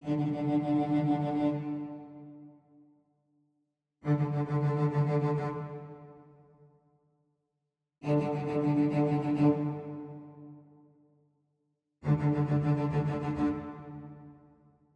You will hear v 1.0.1 before and 1.1 after.
In the spiccatos MP3 I shortened the notes a bit more and I slowed down tempo to 80 bpm and then 60 bpm (the first is at 120 bpm) and the effect is more present, am I doing something wrong?